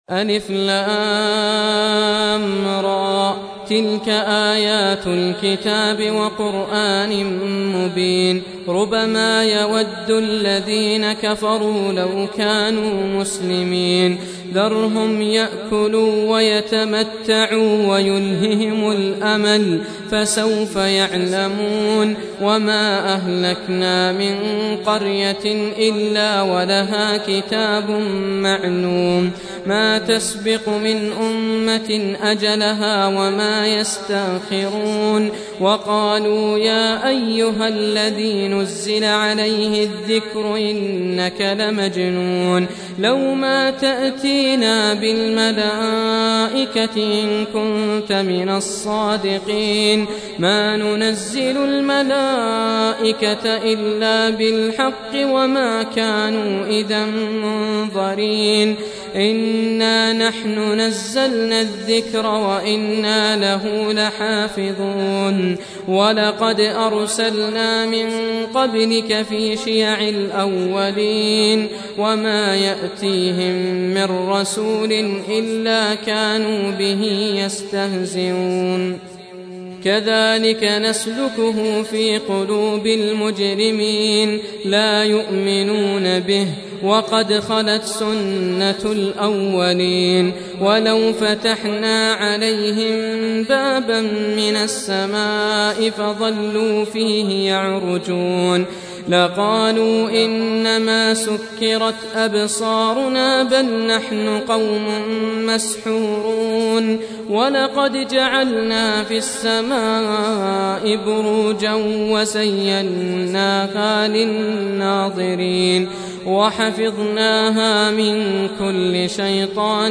15. Surah Al-Hijr سورة الحجر Audio Quran Tarteel Recitation
Surah Repeating تكرار السورة Download Surah حمّل السورة Reciting Murattalah Audio for 15. Surah Al-Hijr سورة الحجر N.B *Surah Includes Al-Basmalah Reciters Sequents تتابع التلاوات Reciters Repeats تكرار التلاوات